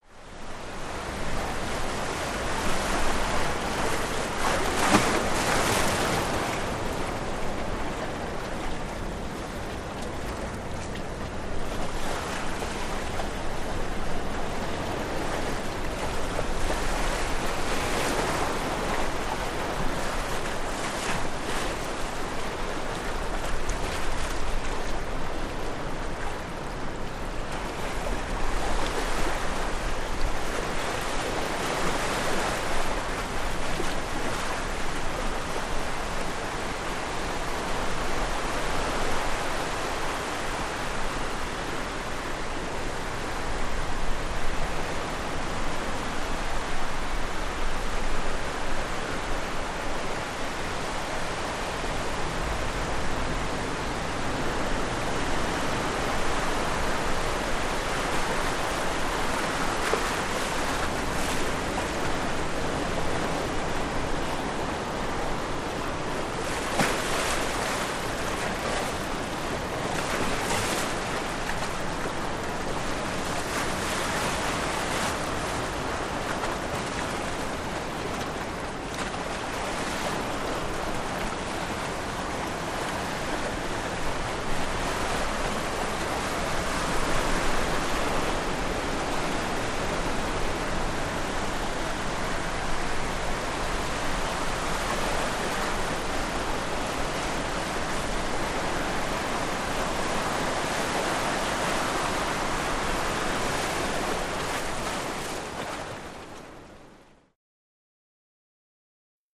Wave Crashes
Close Up On Mediterranean Sea, Small Waves Through Rocky Cracks.